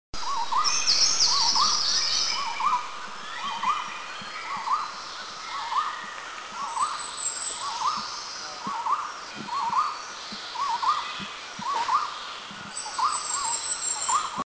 Megalaima franklinii
Golden-throated Barbet
Golden-throatedBarbet.mp3